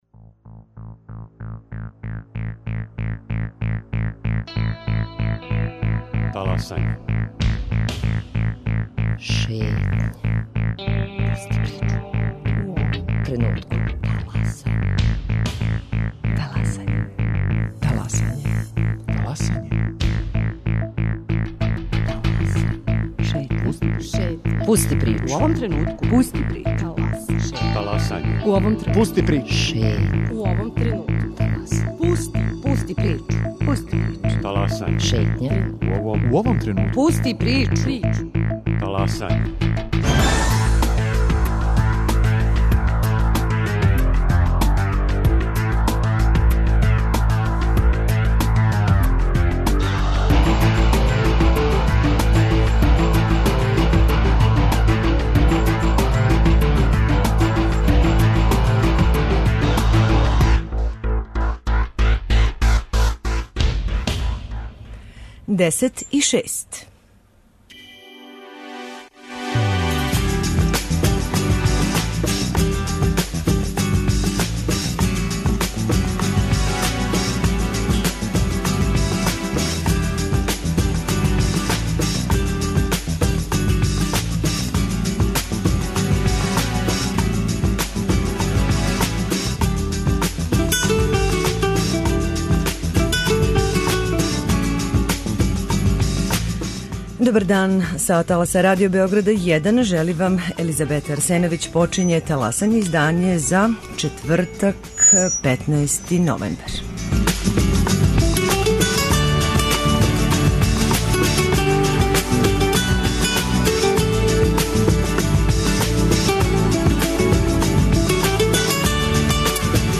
Таласање - Шетња | Радио Београд 1 | РТС